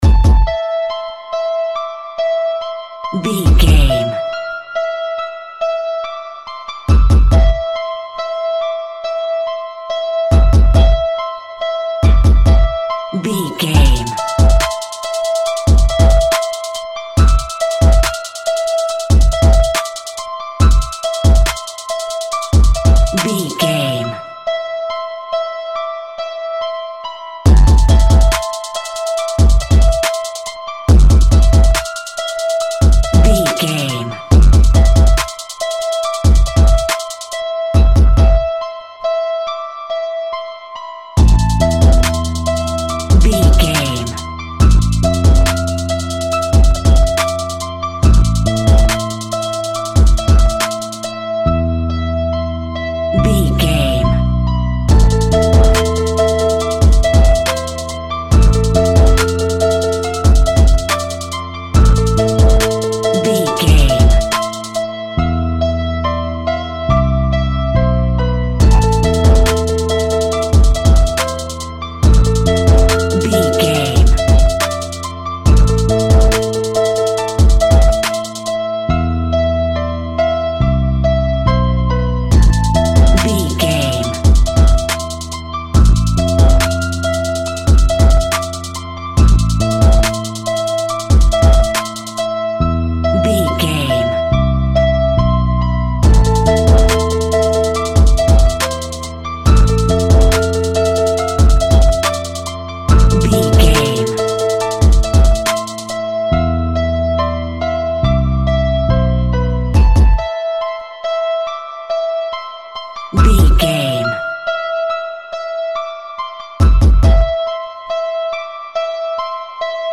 Hip Hop Scary Music Cue.
Aeolian/Minor
Fast
ominous
eerie
industrial
drum machine
piano
bass guitar
synthesiser